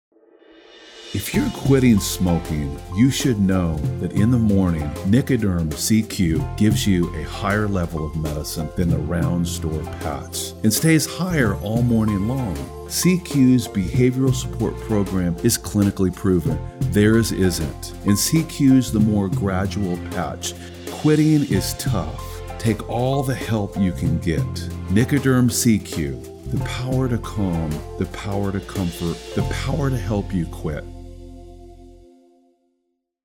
talented male voiceover artist known for his warm, rich tone that captivates listeners